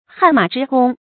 汗马之功 hàn mǎ zhī gōng
汗马之功发音
成语注音 ㄏㄢˋ ㄇㄚˇ ㄓㄧ ㄍㄨㄙ